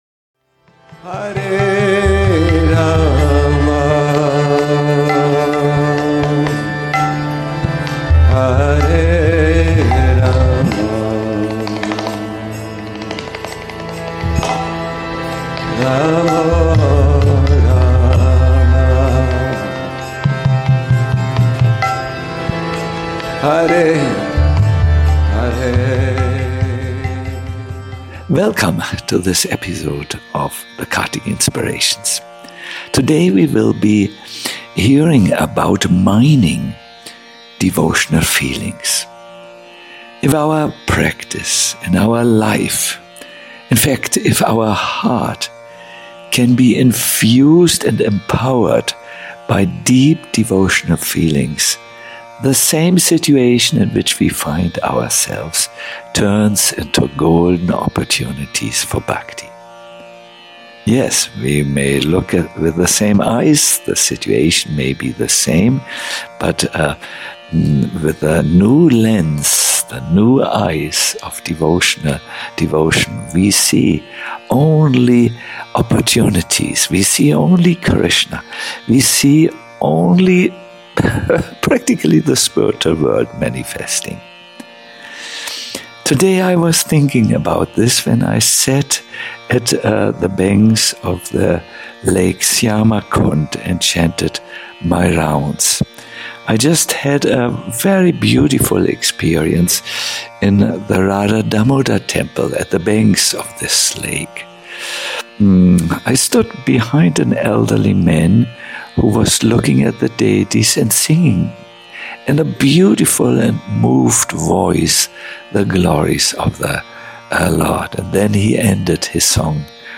A lecture